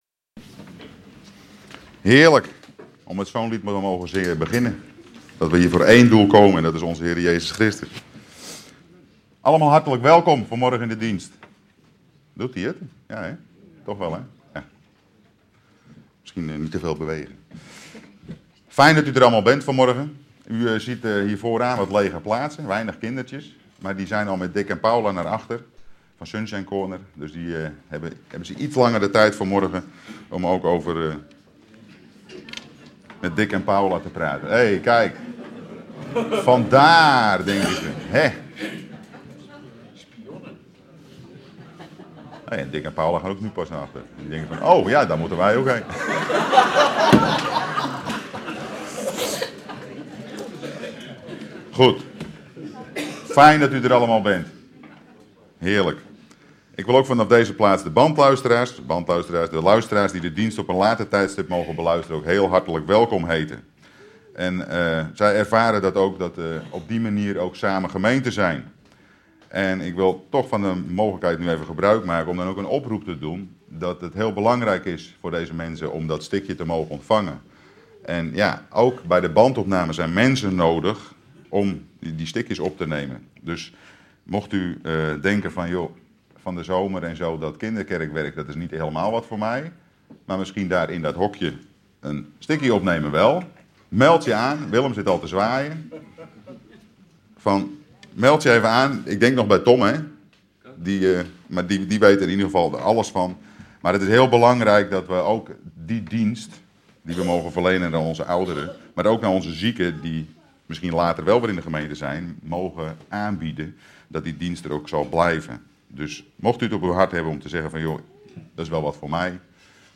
Kerkdiensten |
Afluisteren van de Diensten De diensten kunnen als een livestream bekeken worden, dus gelijktijdig met de dienst in de gemeente.